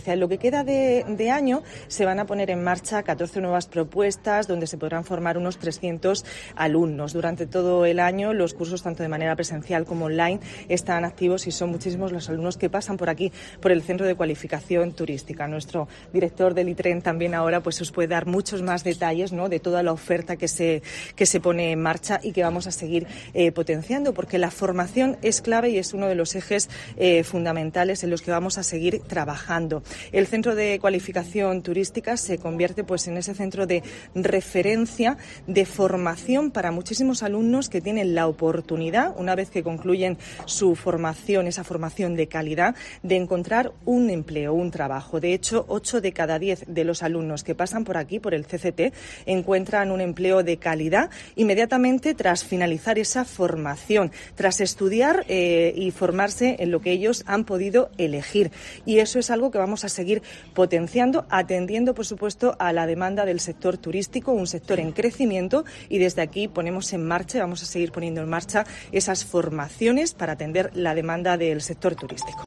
Carmen Conesa, consejera de Turismo, Cultura, Juventud y Deportes